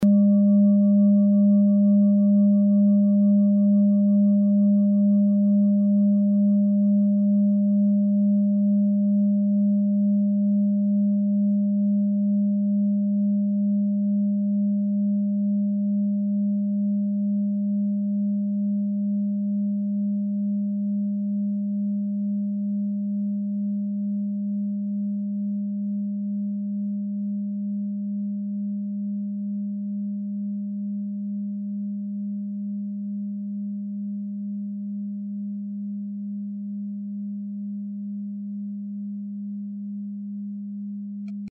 Klangschalen-Typ: Bengalen und Tibet
Klangschale 2 im Set 5
Klangschale Nr.2
(Aufgenommen mit dem Filzklöppel/Gummischlegel)
klangschale-set-5-2.mp3